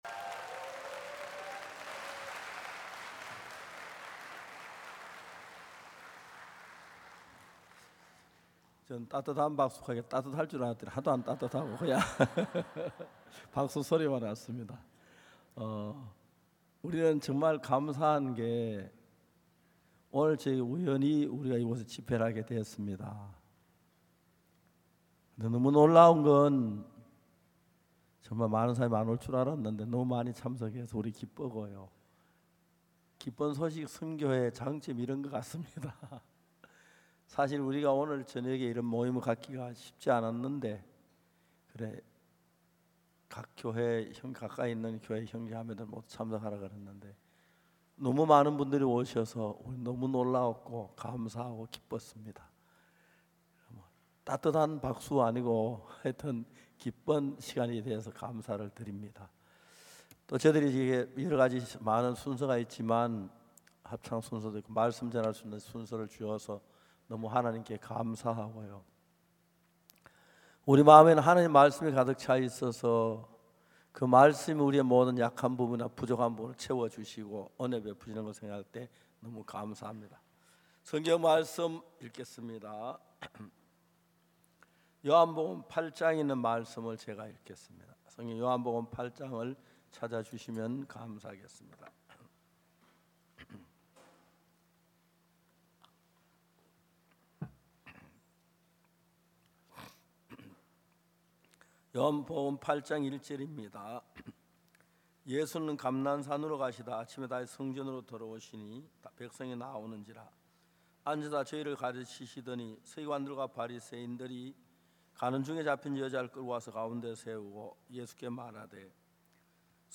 성경세미나 설교를 굿뉴스티비를 통해 보실 수 있습니다.